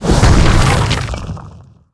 metinstone_crash.wav